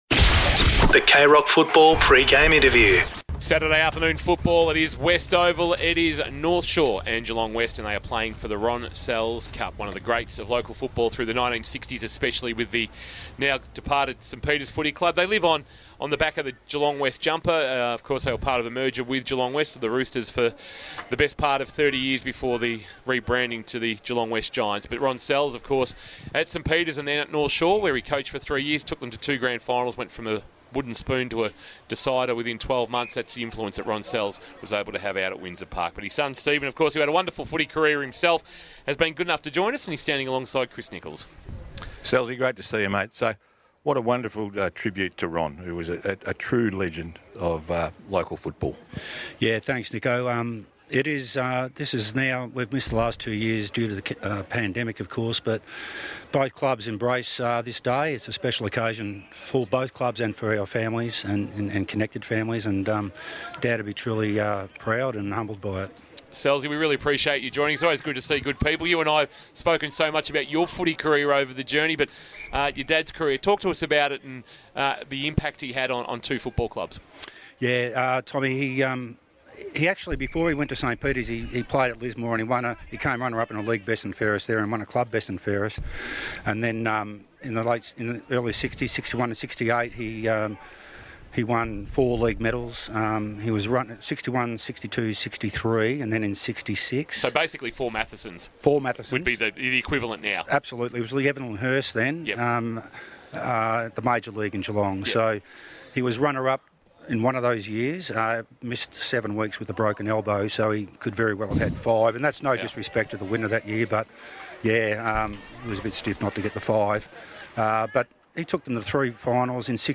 2022 – GFL ROUND 9 – GEELONG WEST vs. NORTH SHORE: Pre-match Interview